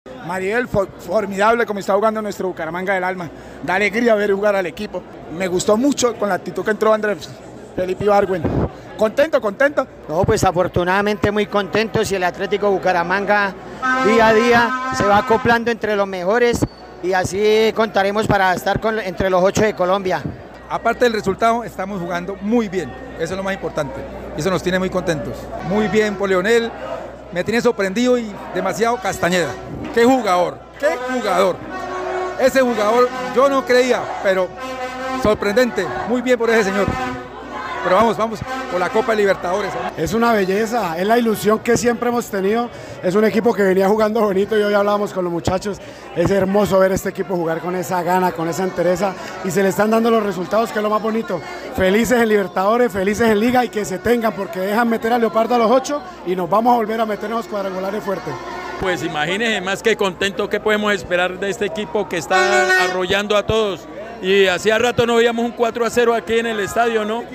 Hinchas de Atlético Bucaramanga tras la victoria ante Fortaleza FC
Voces de la afición
Al finalizar el encuentro los hinchas presentes en el estadio de la Ciudad Bonita, expresaron su alegría por la actualidad del equipo búcaro.